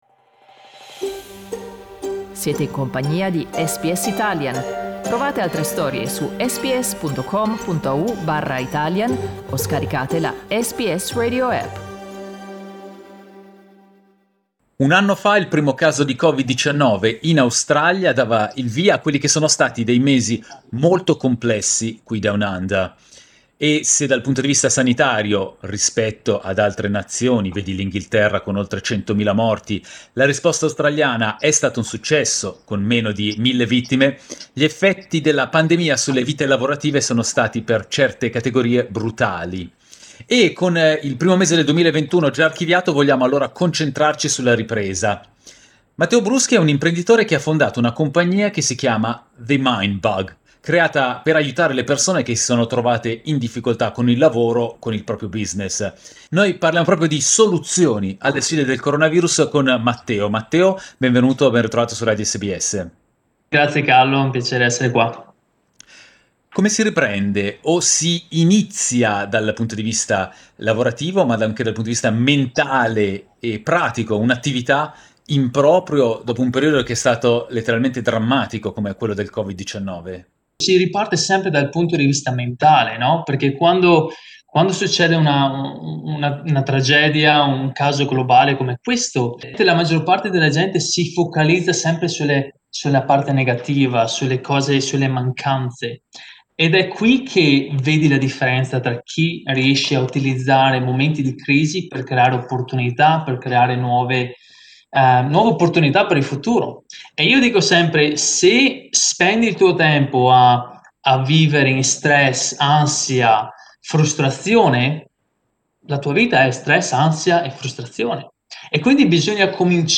Ascoltiamolo ai microfoni di SBS Italian.